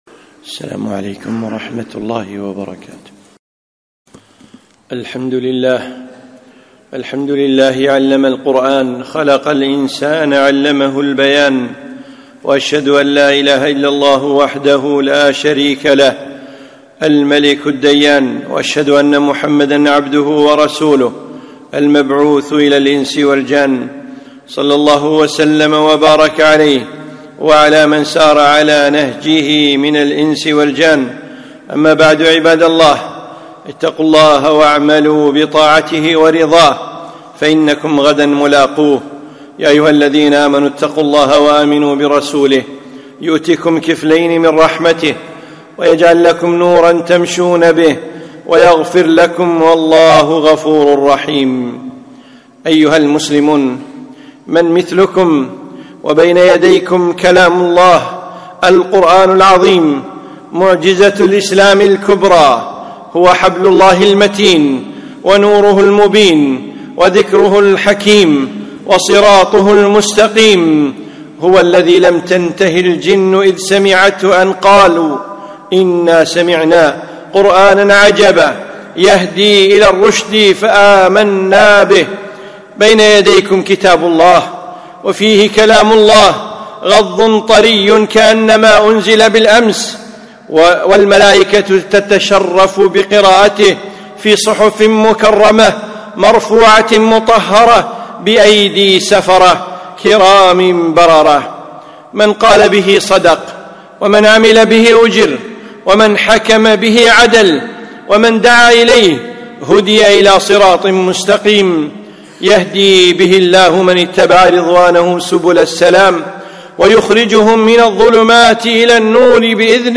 خطبة - حبل الله